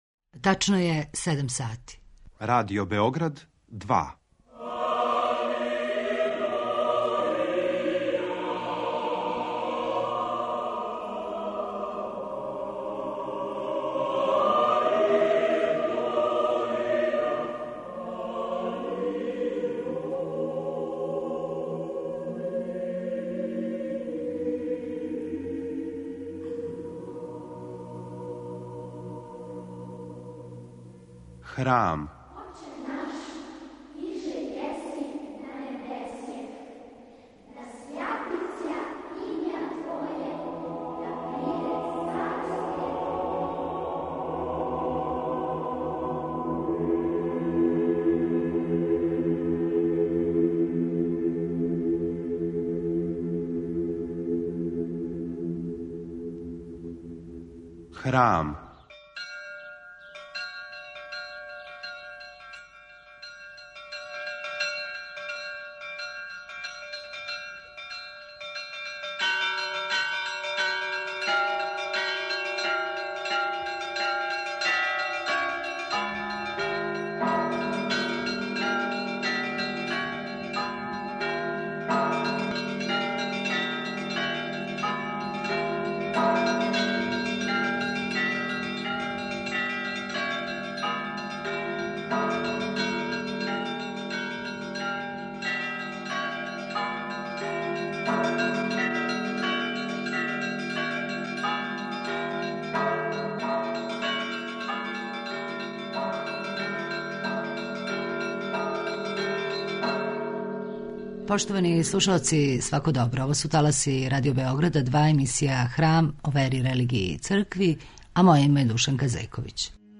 У данашњем Храму слушате пaтријарха српског Иринеја, надбискупа београдског Станислава Хочевара и викарног епископа топличког Арсенија.